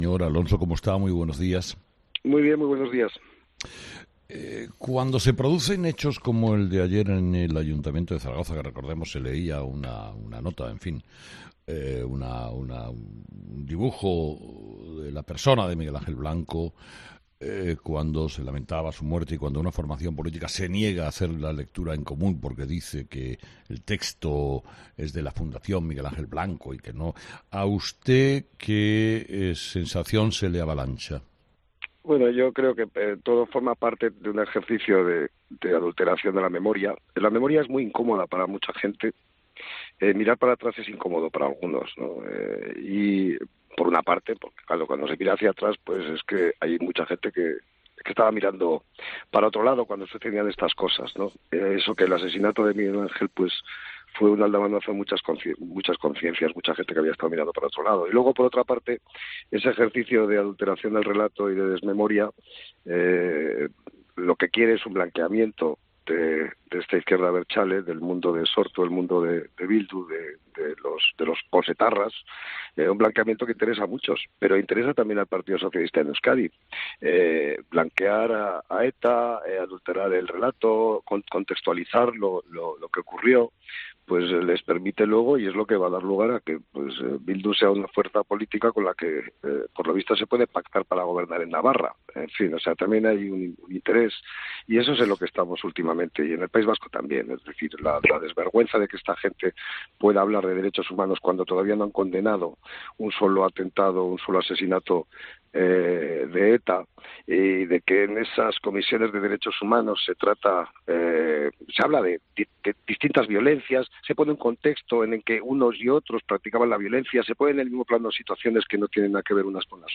Entrevista: Alfonso Alonso COPE
El presidente del PP en el País Vasco, Alfonso Alonso, ha señalado en Herrera en COPE que la negativa de Zaragoza en Común de participar en el homenaje a Miguel Ángel Blanco en la ciudad aragonesa “forma parte del ejercicio de querer alterar la memoria. Mirar hacia atrás sigue siendo incómodo para algunos”.